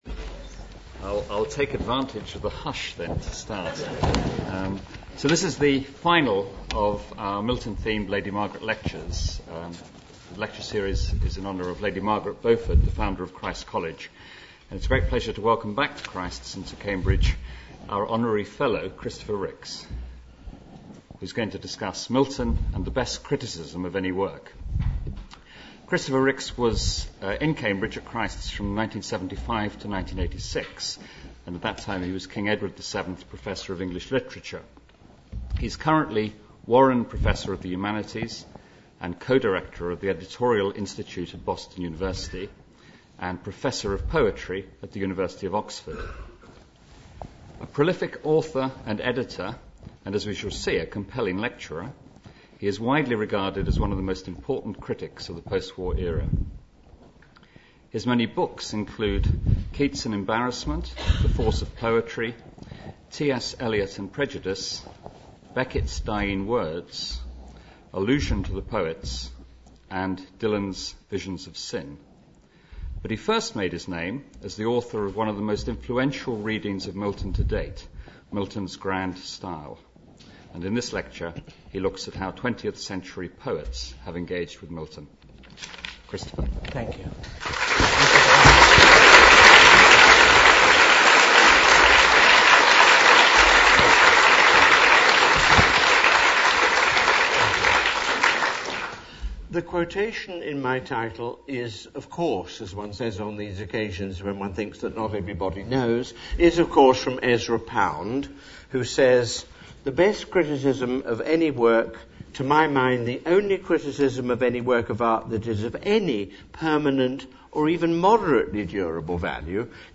Christopher Ricks's lecture, given on 26 November 2008, can be listened to here.